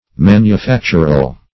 \Man`u*fac"tur*al\